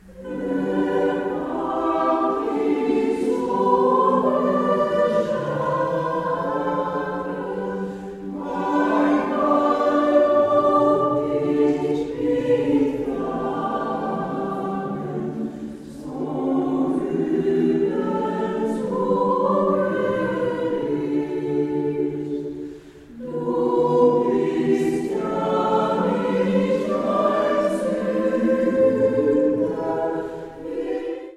– en fyrstämmig, blandad kör med ambitioner att utvecklas,
Smakprov ur höstkonserten
2014-10-19 i Bergshamra kyrka